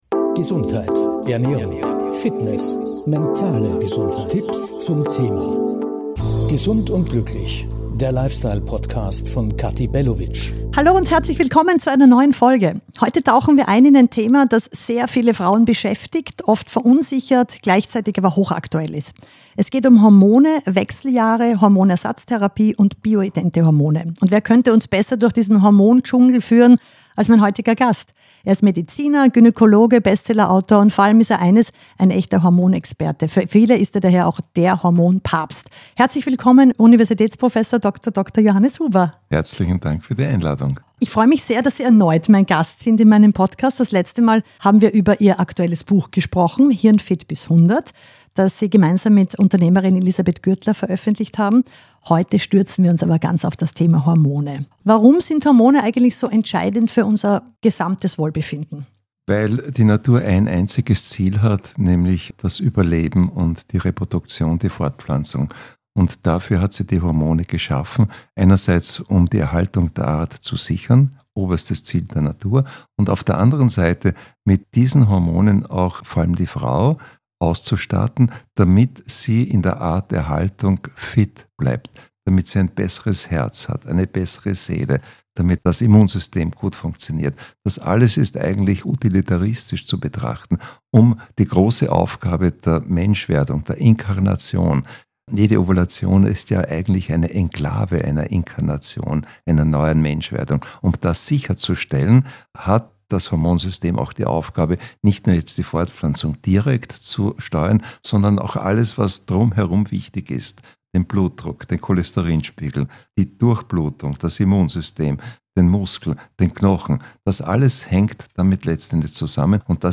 Univ. Prof. DDr. Johannes Huber spricht mit mir über Hormone, Wechseljahre, Hormonersatztherapie und bioidente Hormone.